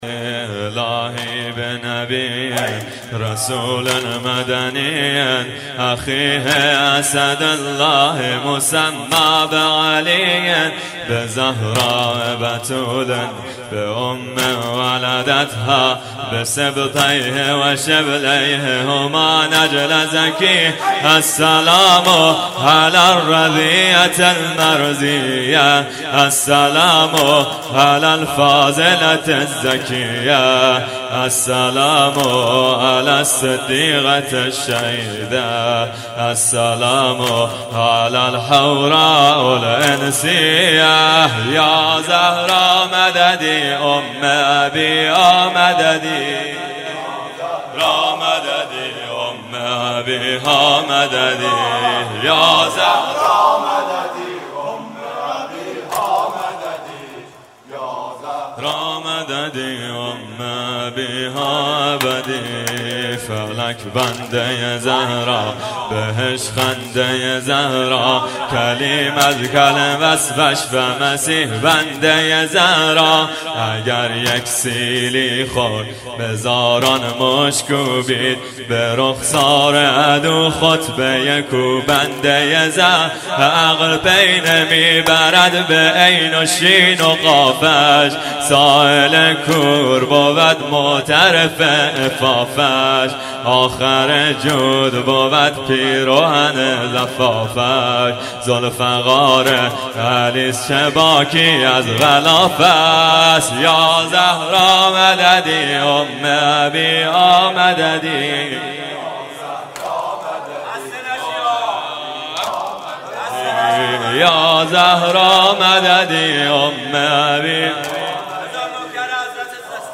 مراسم شب هفتم فاطمیه دوم 93/94(شب تحویل سال نو)